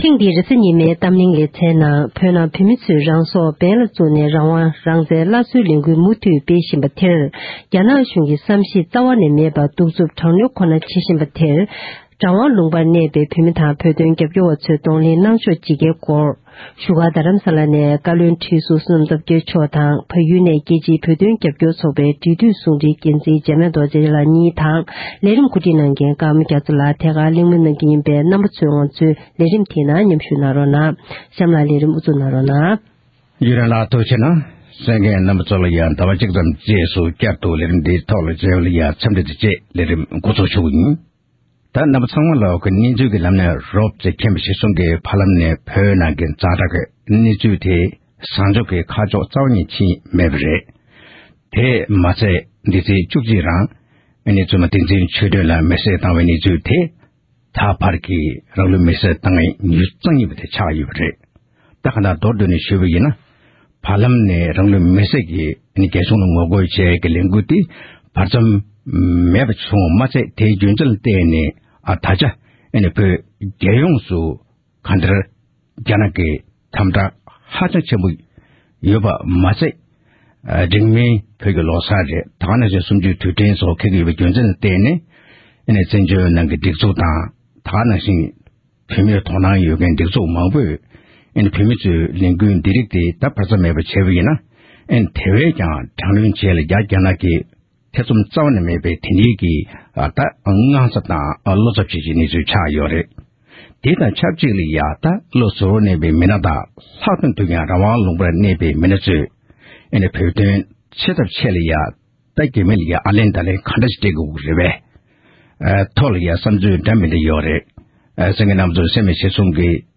༄༅༎ཐེངས་འདིའི་རེས་གཟའ་ཉི་མའི་གཏམ་གླེང་གི་ལེ་ཚན་ནང་དུ་བོད་ནང་བོད་མི་ཚོས་རང་སྲོག་འབེན་ལ་བཙུགས་ནས་རང་དབང་རང་བཙན་བསྐྱར་གསོའི་ལས་འགུལ་མུ་མཐུད་ནས་སྤེལ་བཞིན་པ་དེར་རང་དབང་ལུང་པར་གནས་པའི་བོད་མིའི་སྒྲིག་འཛུགས་དང་རྒྱལ་སྤྱིའི་ཐོག་ནས་གདོང་ལེན་གནང་ཕྱོགས་ཇི་དགའི་སྐོར་རྡ་རམ་ས་ལ་ནས་འདི་གའ་བློ་བསྡུར་ཞུ་ཡུལ་བཀའ་བློན་ཁྲི་ཟུར་བསོད་ནམས་སྟོབས་རྒྱལ་མཆོག་དང་།